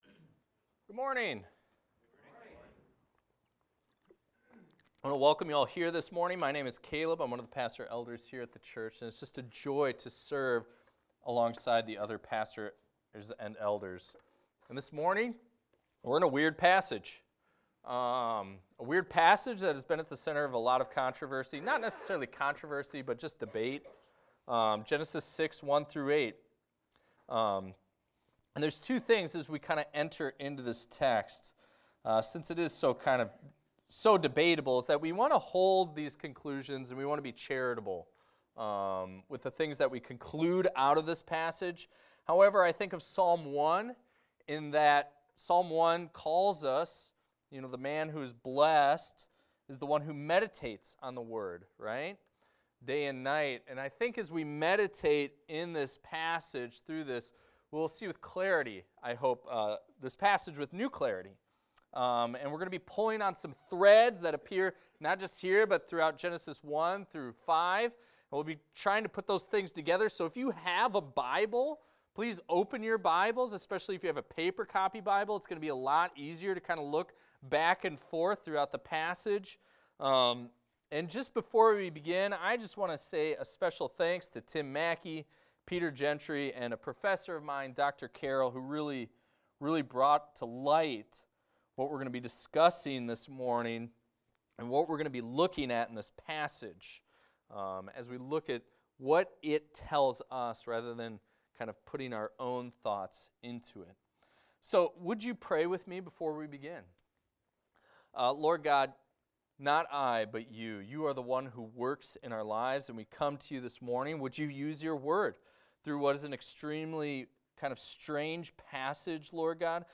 This is a recording of a sermon titled, "God Grieved."